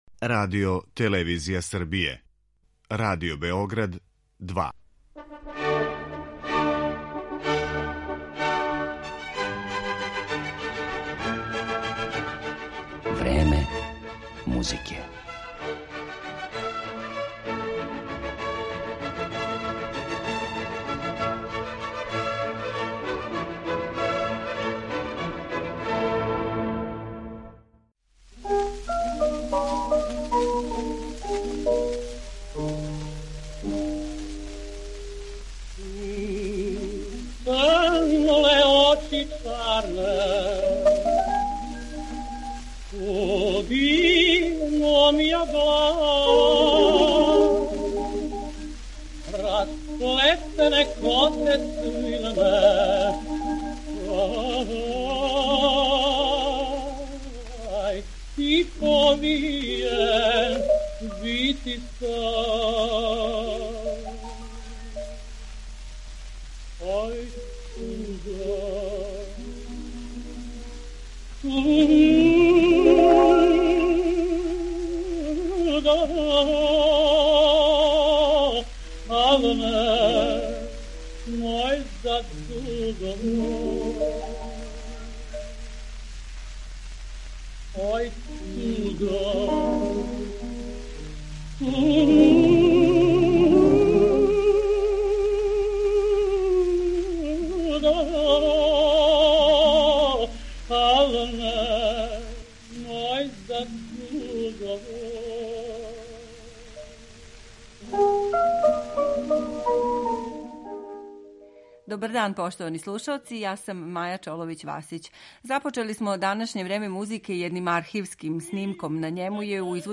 Његов опус ћемо, осим поменутим делима, представити и хорским и оркестарским композицијама, као и соло песмама.